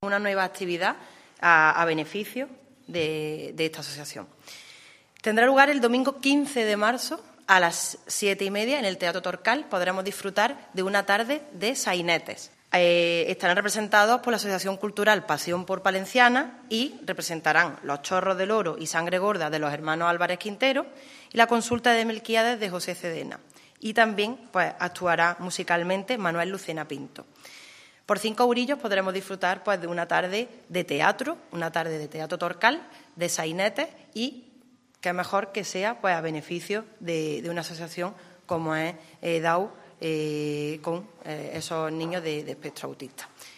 La teniente de alcalde delegada de Cultura, Ferias, Tradiciones y Juventud, Elena Melero, ha presentado en la mañana de hoy una nueva actividad lúdico cultural con un denotado carácter solidario que acogerá el Teatro Torcal el domingo 15 de marzo a las 19:30 horas.
Cortes de voz E. Melero 298.39 kb Formato: mp3